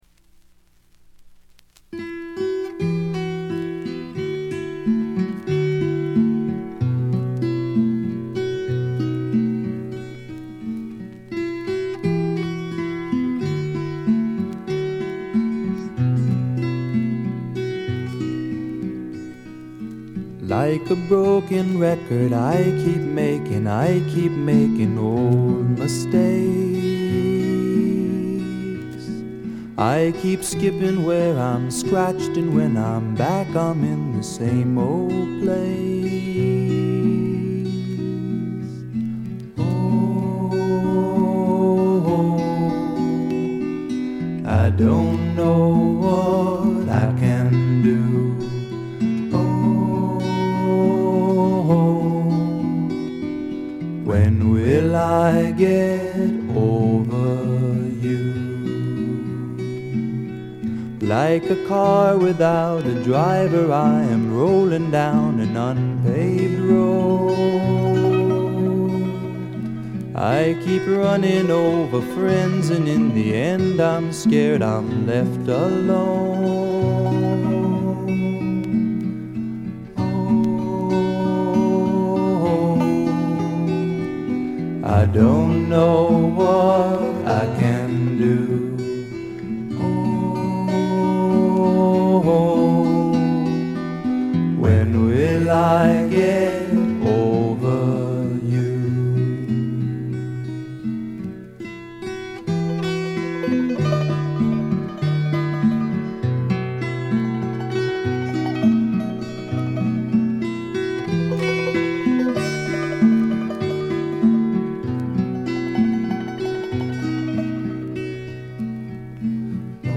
軽微なチリプチ少々。
しかし内容はいたってまともなフォーキー・シンガー・ソングライター作品です。
いかにも東部らしい静謐な空気感がただようなかに、愛すべきいとおしい曲が散りばめられた好盤です。
試聴曲は現品からの取り込み音源です。